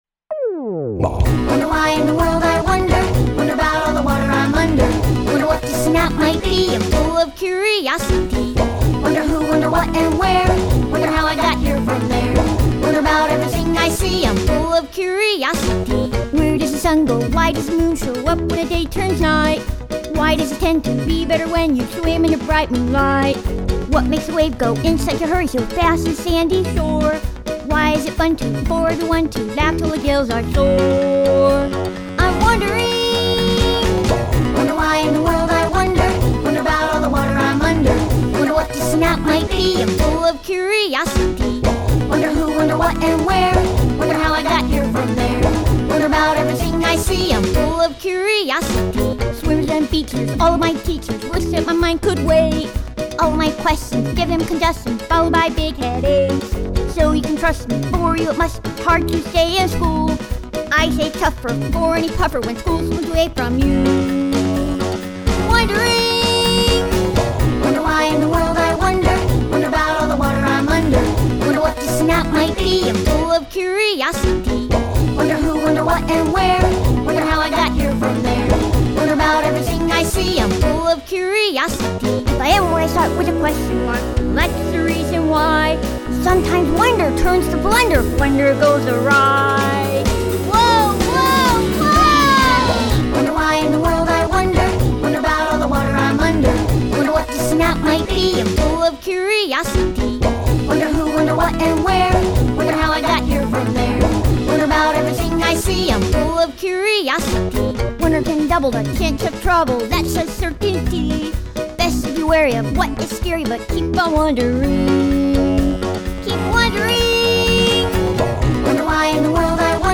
Voice quality: Youthful, vibrant, energetic, quirky, sincere, genuine, sweet, deadpan.
singer